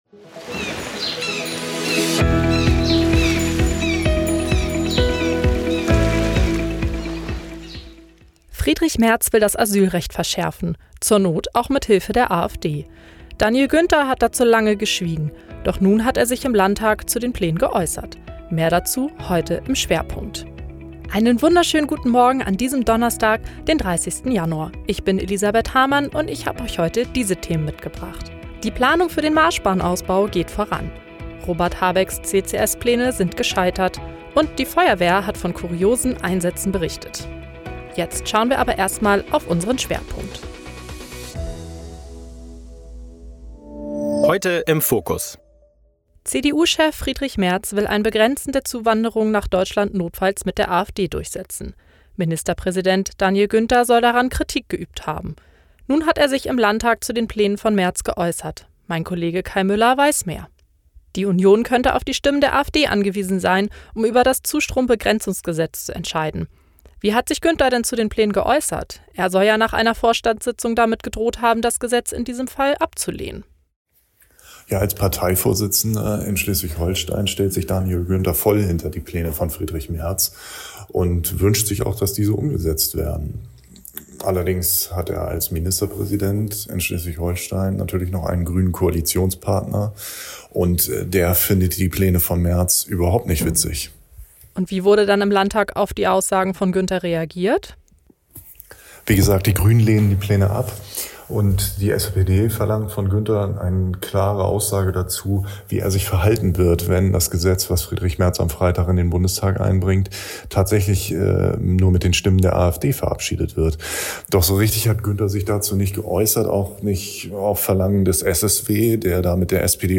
Nachrichten
Jetzt spricht er dazu im Landtag.